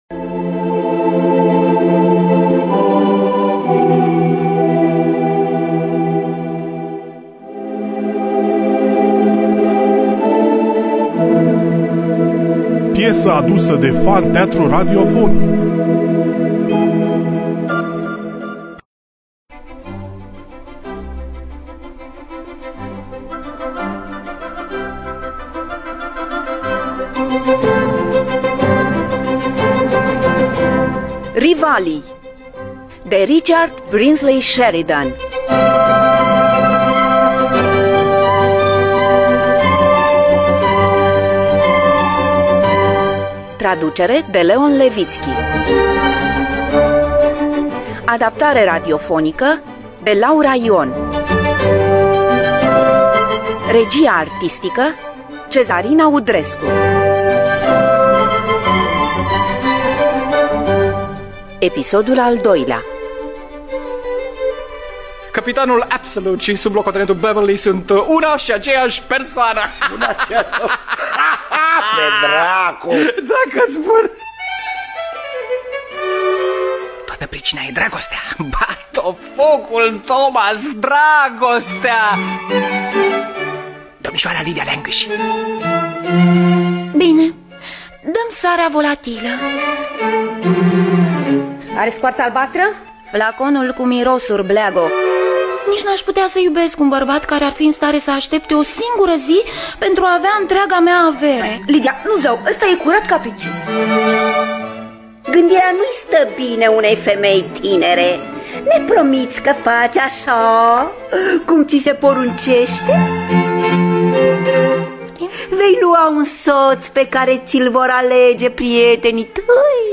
Richard Brinsley Sheridan – Rivalii (2003) – Episodul 2 – Teatru Radiofonic Online